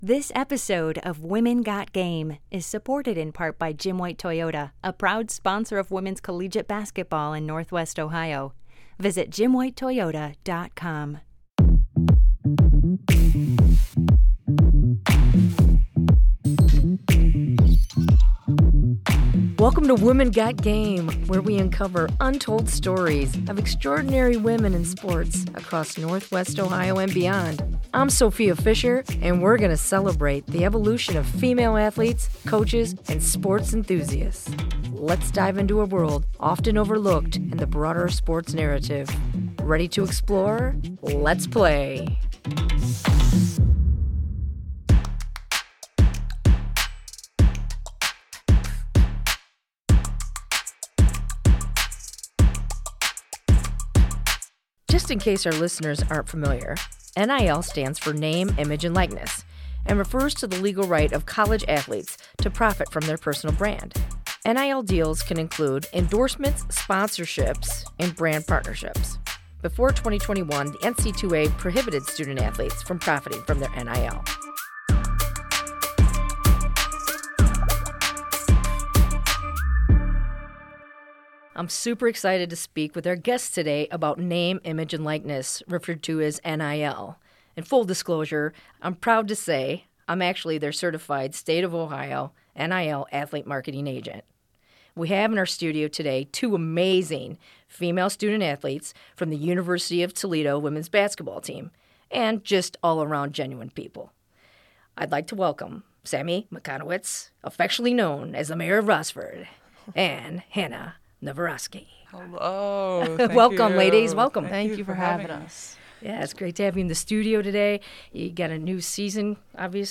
/*-->*/ /*-->*/ In this episode we discuss how Name, Image, and Likeness (NIL) is changing the game for college athletes—especially for female players. This week, we’re thrilled to have two University of Toledo basketball stars, sharing their journey with NIL. We’ll share how these athletes are using NIL to shape their careers, build personal brands, and inspire younger athletes.